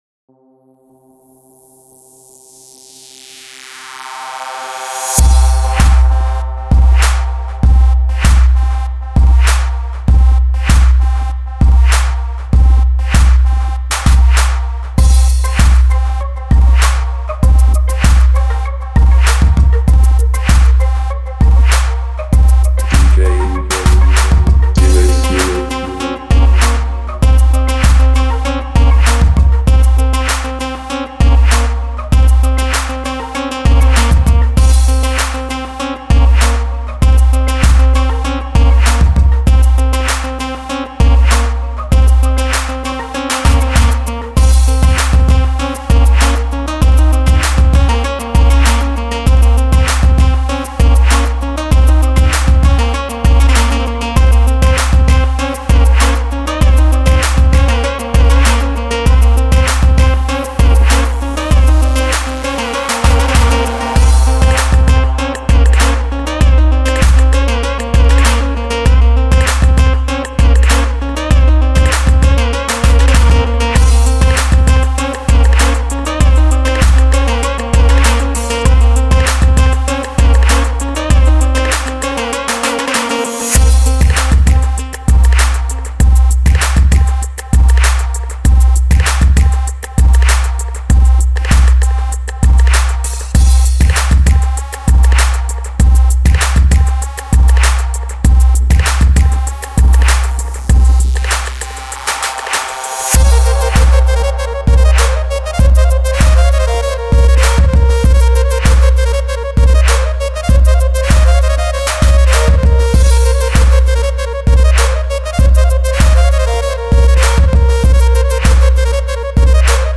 BASS_sambufer.mp3